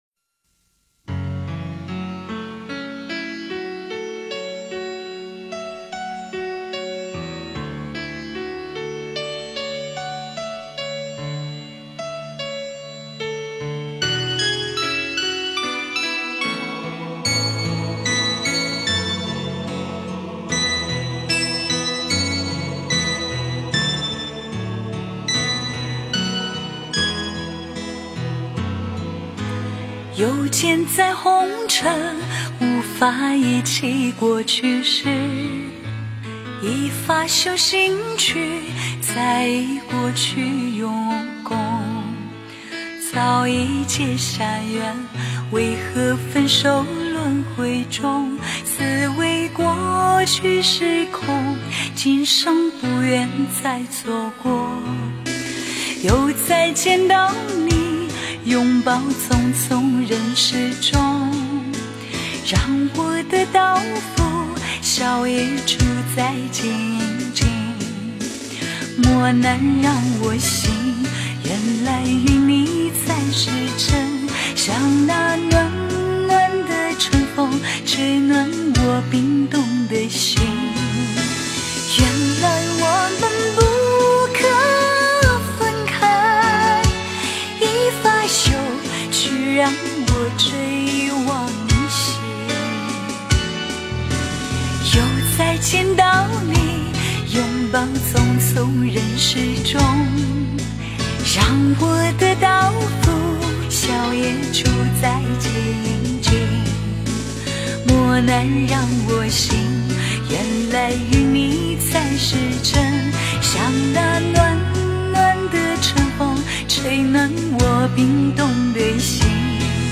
追忆 诵经 追忆--佛教音乐 点我： 标签: 佛音 诵经 佛教音乐 返回列表 上一篇： 种法 下一篇： 自在修行 相关文章 无量寿经（女声念诵） 无量寿经（女声念诵）--未知... 24四句偈的智慧--诚敬仁和 24四句偈的智慧--诚敬仁和...